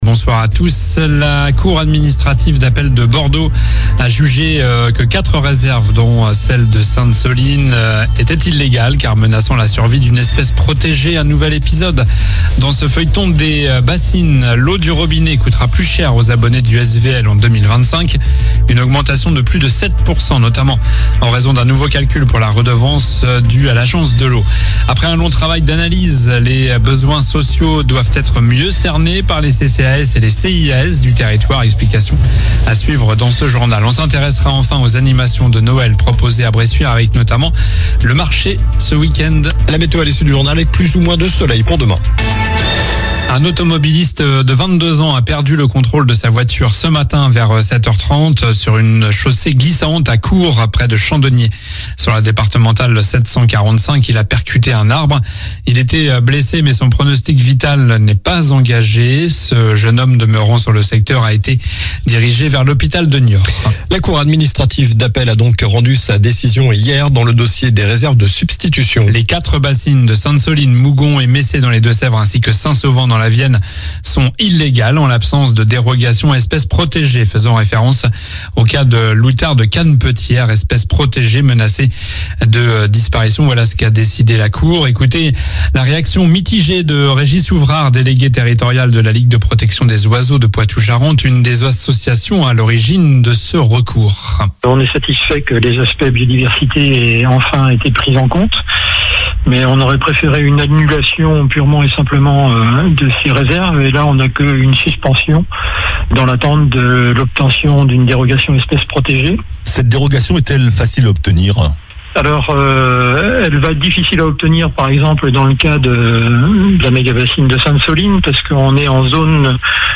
Journal du jeudi 19 décembre (soir)